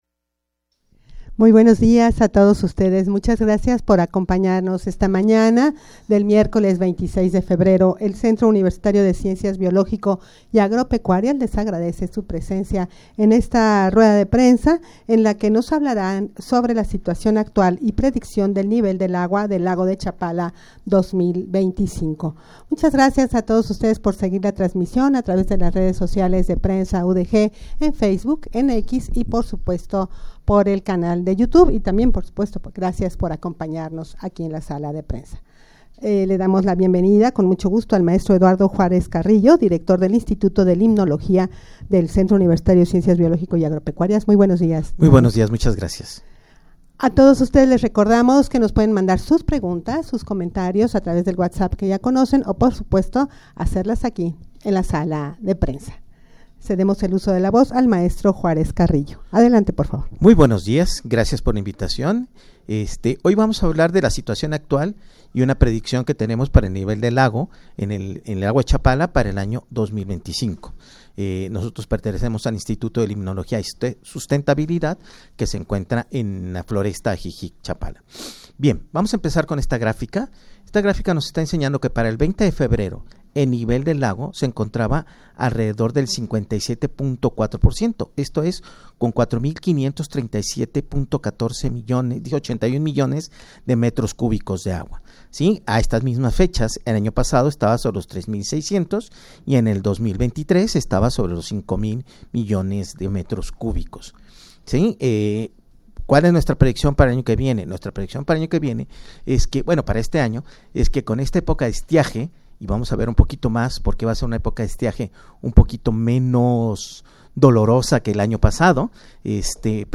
Audio de de la Rueda de Prensa
rueda-de-prensa-situacion-actual-y-prediccion-del-nivel-del-agua-del-lago-de-chapala-2025.mp3